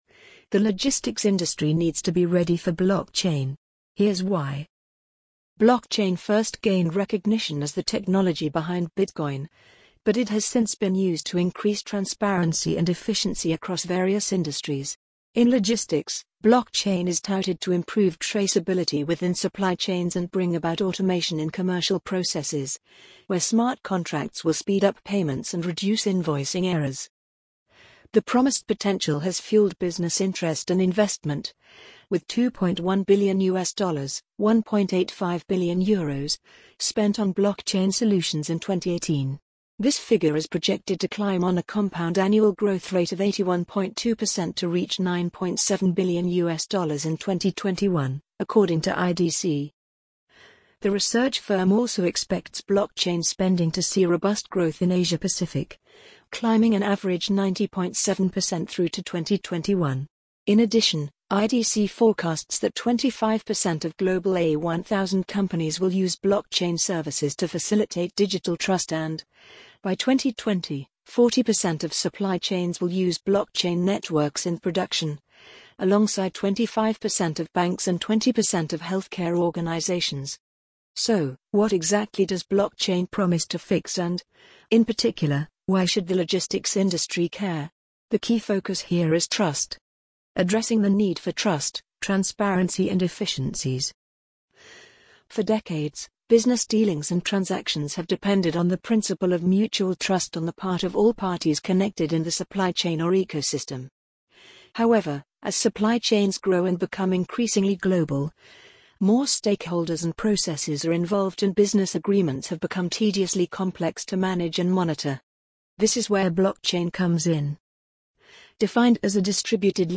amazon_polly_2618.mp3